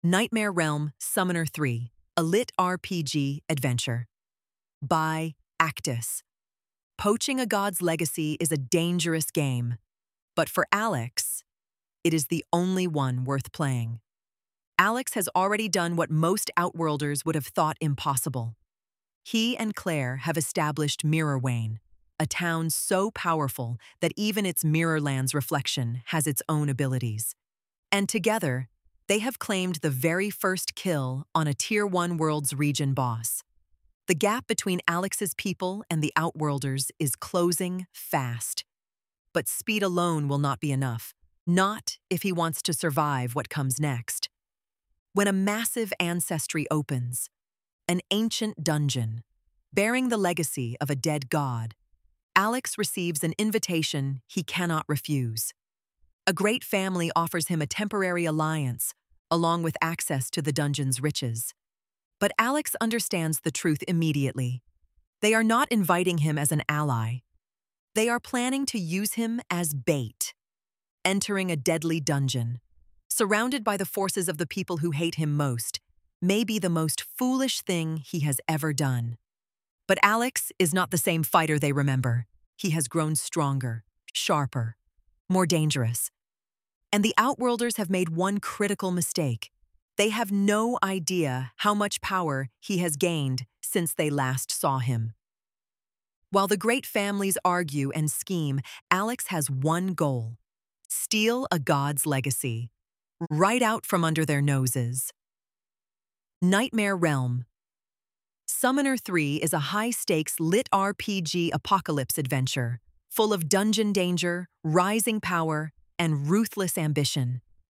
Audiobook Sample
A short promotional audio sample ready to share with your audience.